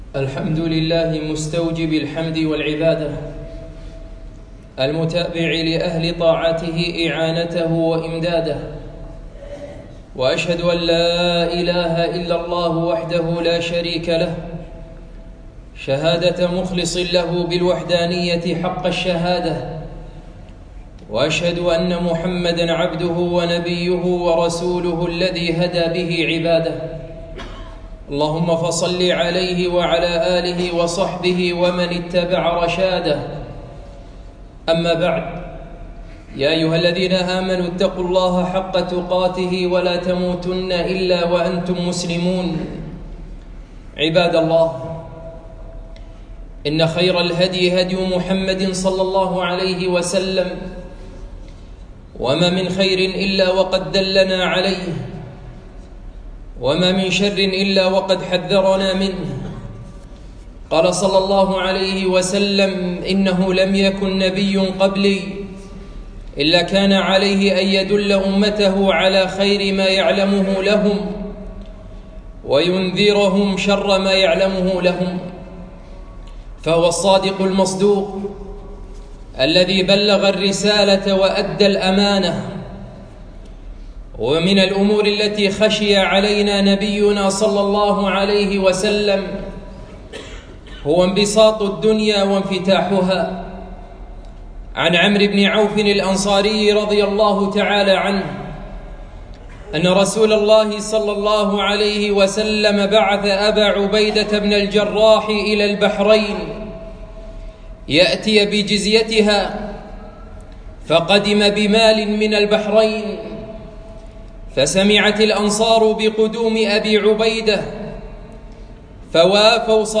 خطبة - ما الفقر أخشى عليكم